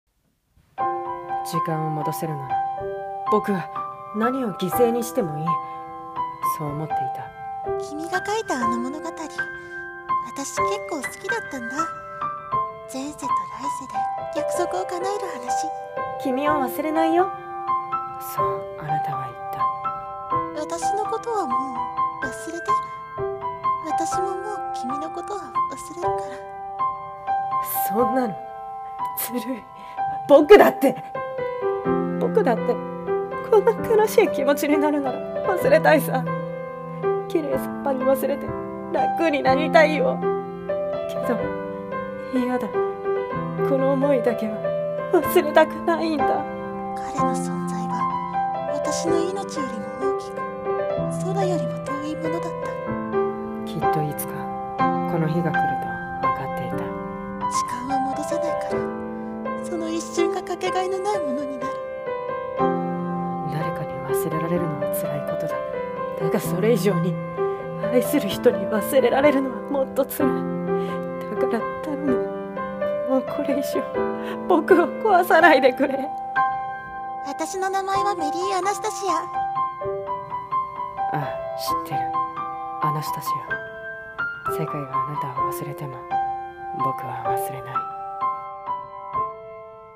映画予告風『5日後、僕は君に出会う』